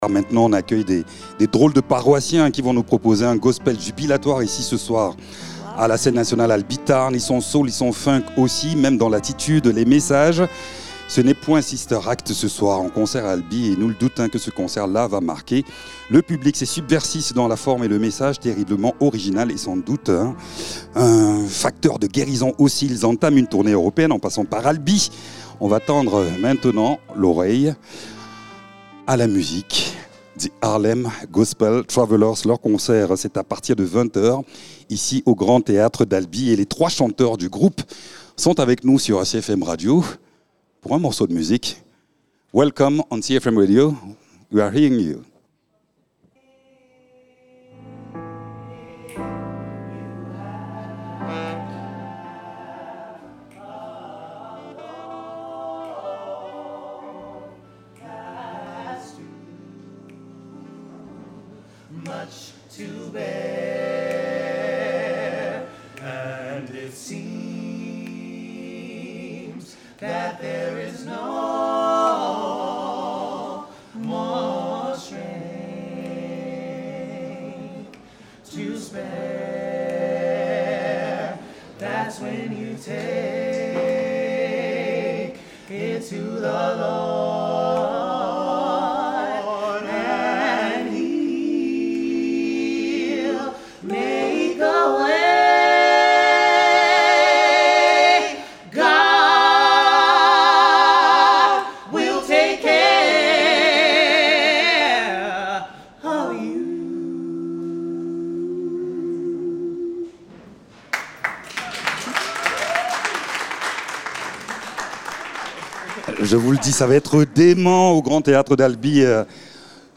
chanteurs du THGT.